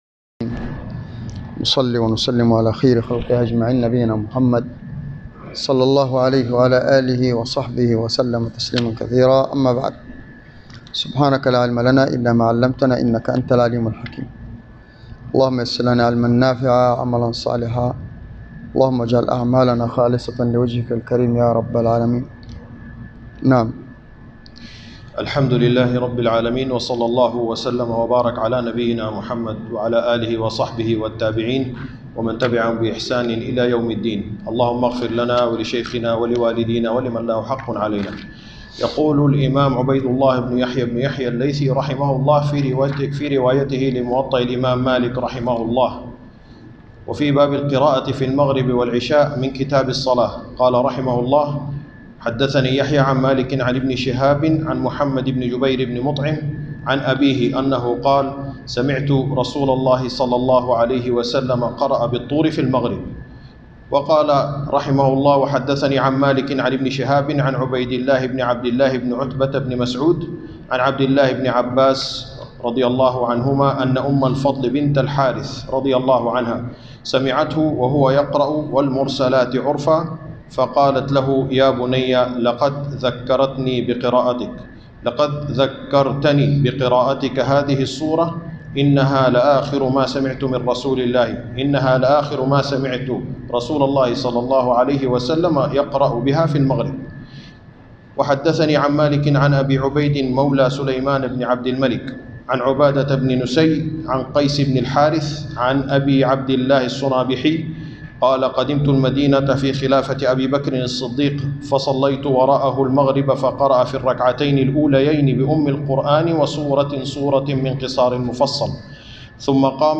الدرس الثالث عشر من كتاب الصلاة - موطأ الإمام مالك _ 13